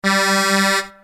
Download Truck sound effect for free.
Truck